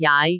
speech
syllable
pronunciation
jaai5.wav